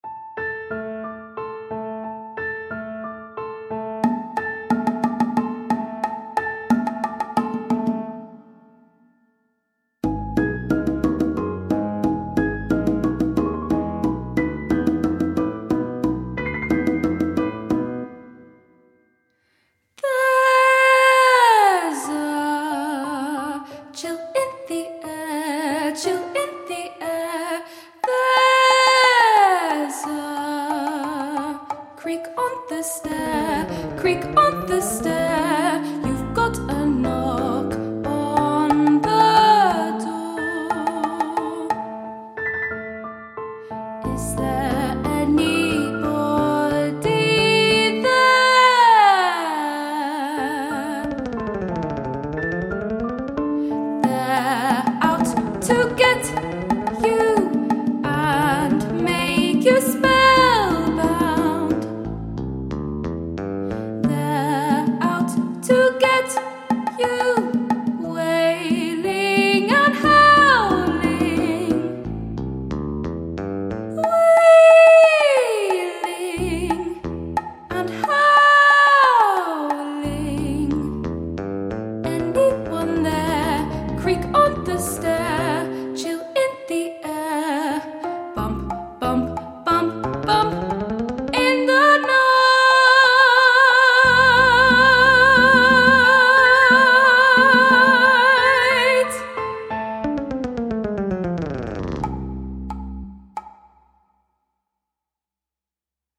The music offers solos, part singing and chorus work.